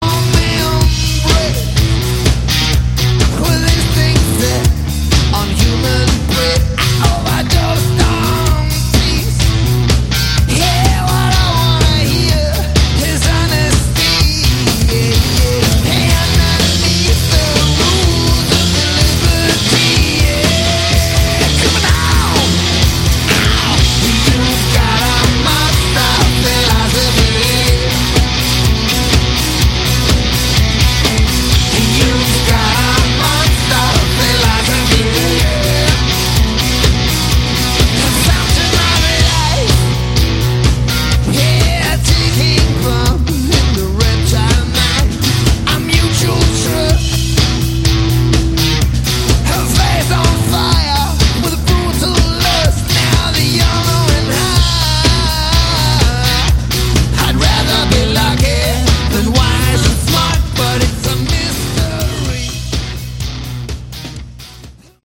Category: Hard Rock
Vocals, Guitar
Drums
Bass
live